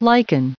1657_liken.ogg